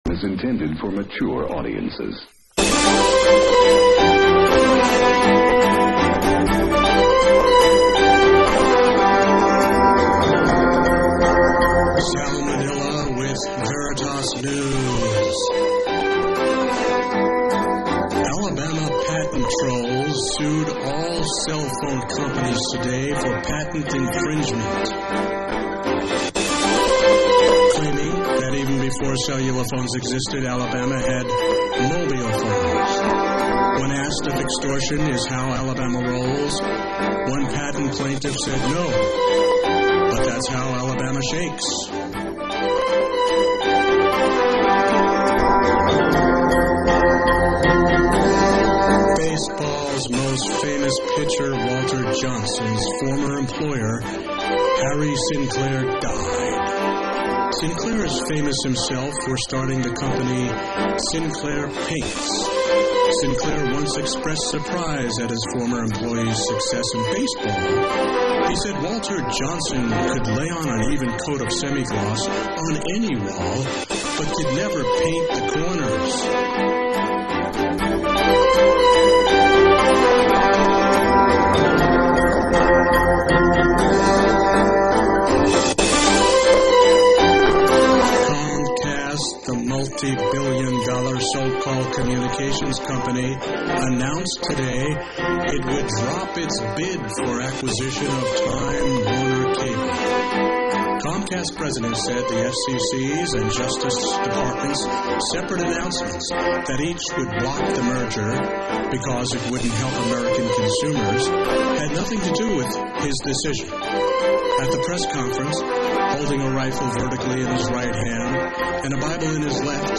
Additionally, humorous clips on the topic of God, Religion, and Atheism by George Carlin and Russell Brand are offered for your enjoyment.